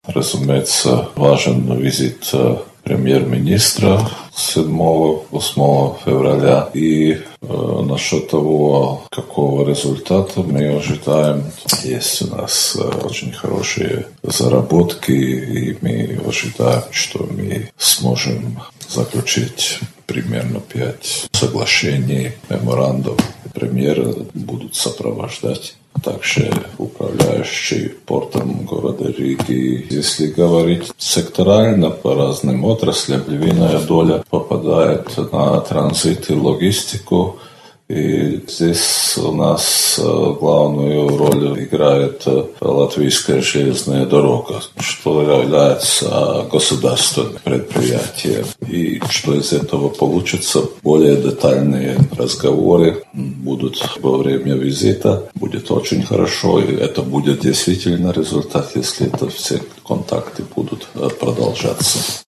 Полномочный посол Латвийской Республики в Республике Беларусь Мартиньш Вирсис (фрагмент интервью)